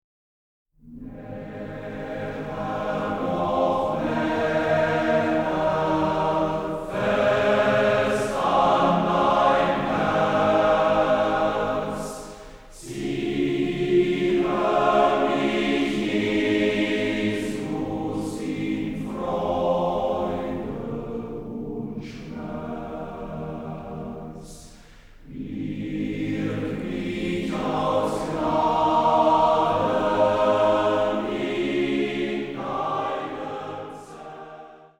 gemischter Chor, Orgel